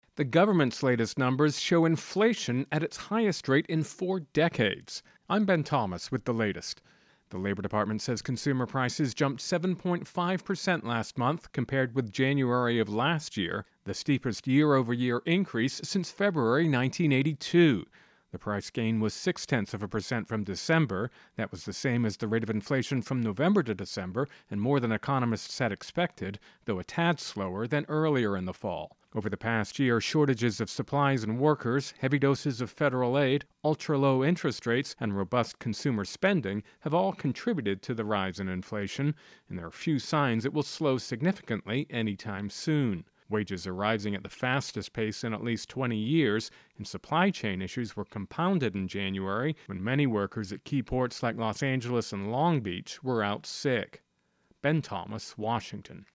Intro and voicer "Consumer Prices"